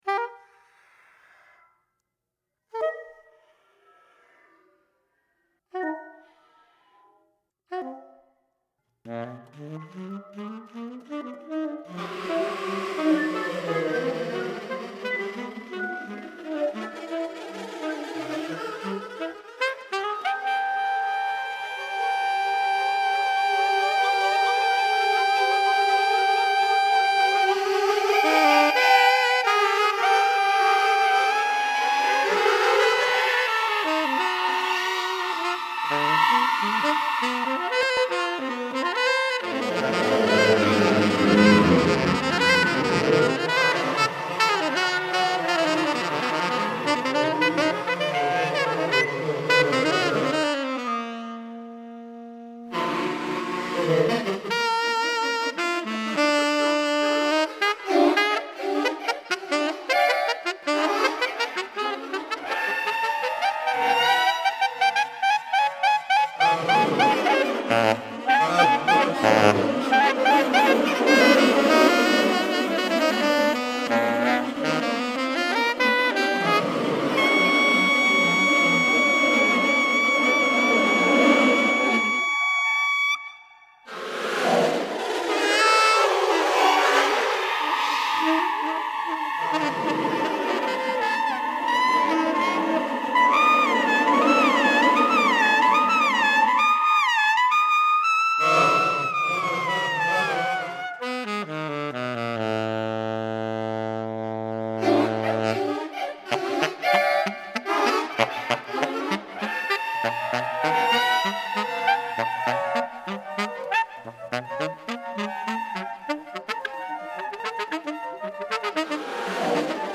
for saxophones and computer
for tenor and soprano saxophones and computer